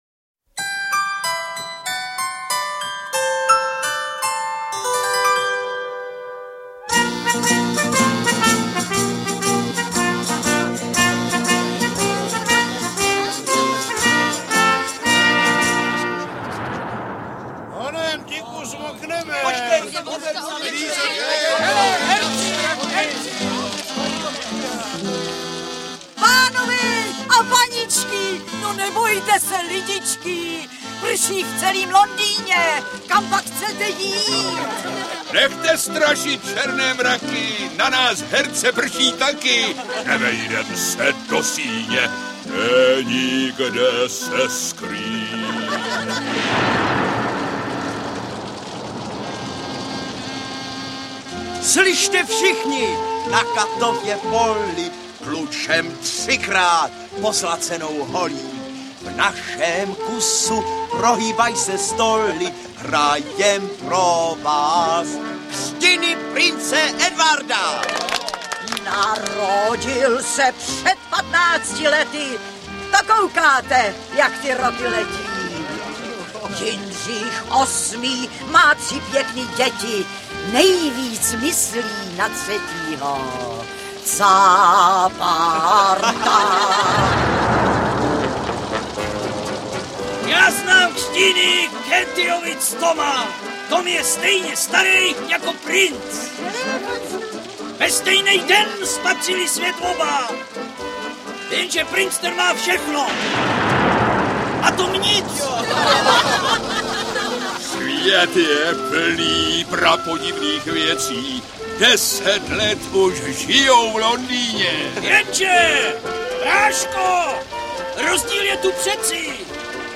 Interpret:  Jaromír Hanzlík
Dramatizace povídky odehrávající se v šestnáctém století v době těsně před smrtí Jindřicha VIII. V titulní roli Jaromír Hanzlík.
AudioKniha ke stažení, 8 x mp3, délka 1 hod. 28 min., velikost 80,6 MB, česky